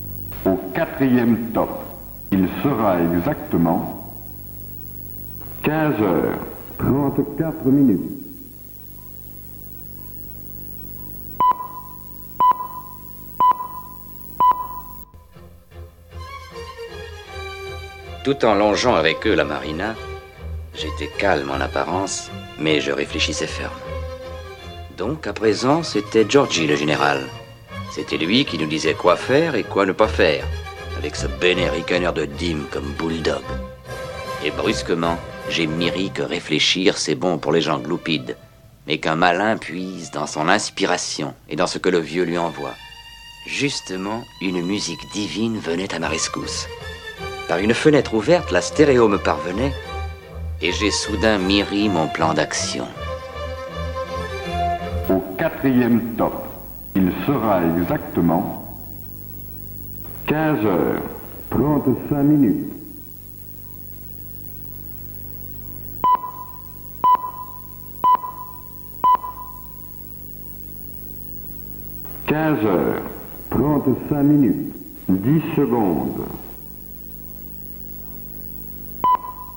Interludes & Jingles pour la radio
Je vous laisse deviner de quel film provient le sample.